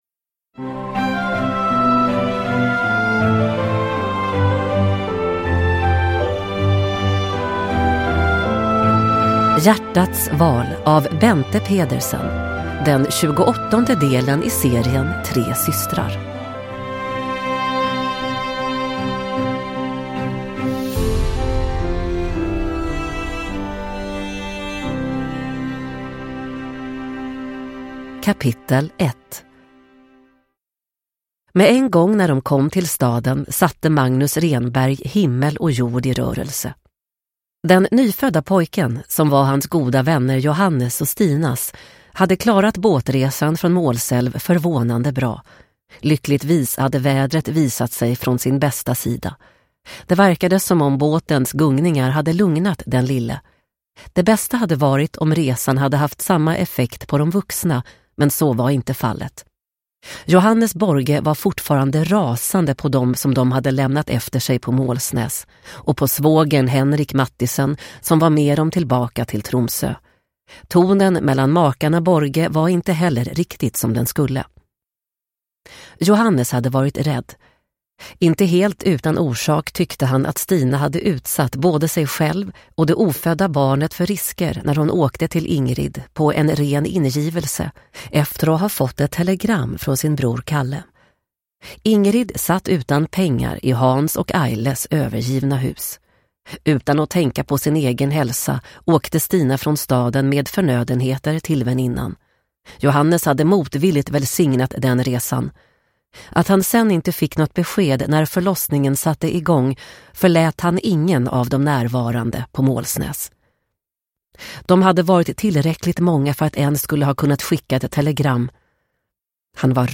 Hjärtats val – Ljudbok – Laddas ner